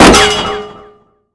ap_hit_veh3.wav